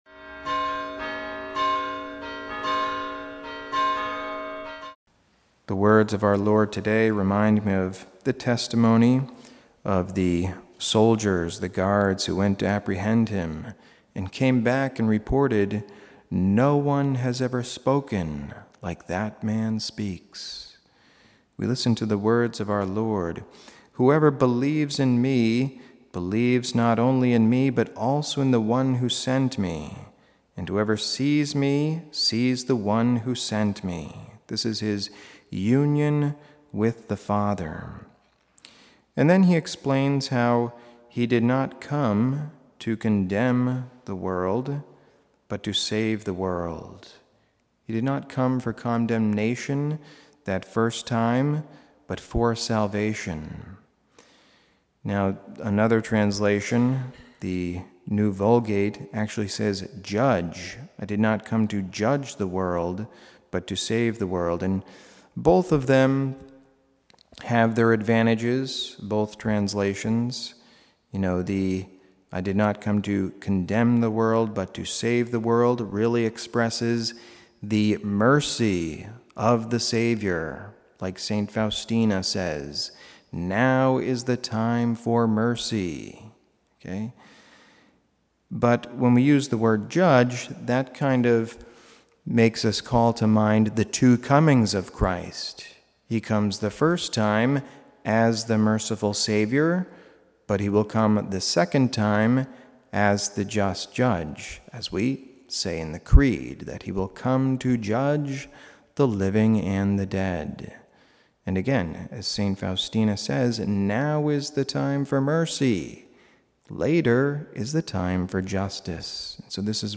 Homily
Mass: Wednesday 4th Week of Easter - Wkdy - Form: OF Readings: 1st: act 12:24-13:5 Resp: psa 67:2-3, 5, 6, 8 0 Gsp: joh 12:44-50 Audio